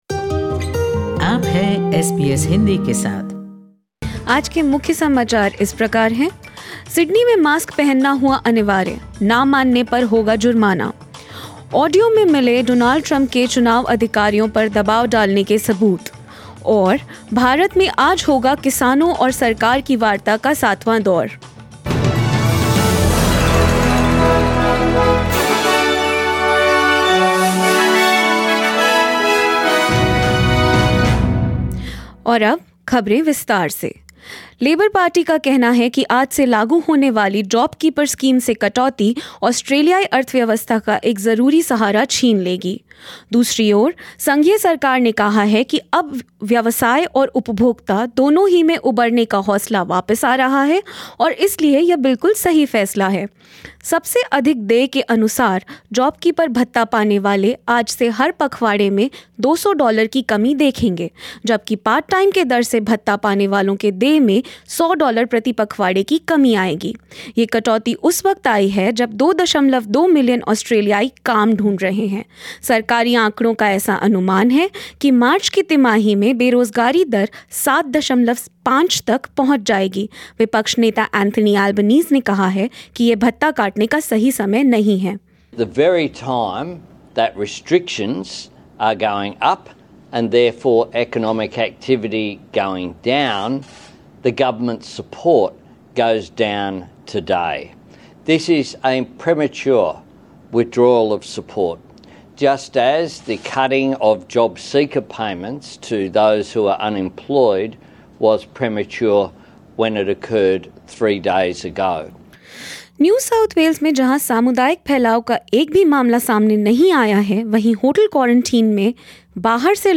News in Hindi 04January 2021 ** Sydney makes it mandatory to wear masks in indoor settings, non-compliance would invite fines.